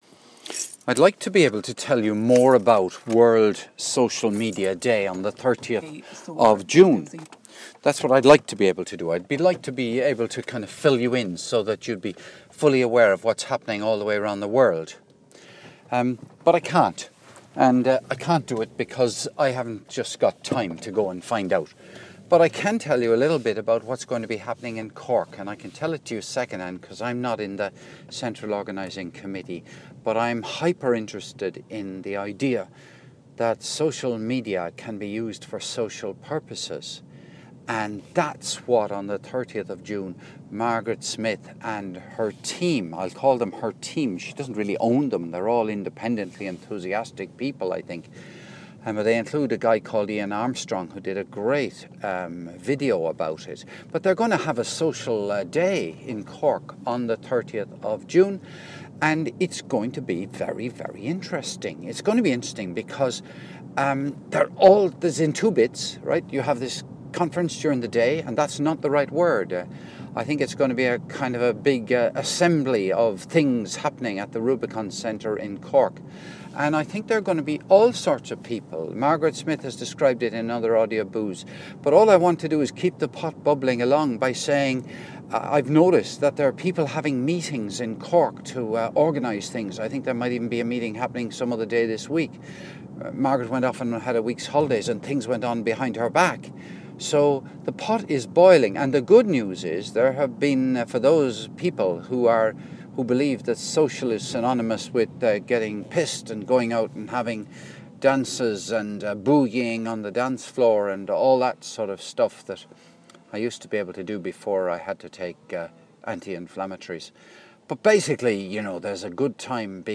World Social Media Day in Cork 30 June - a mad raving rant in favour of it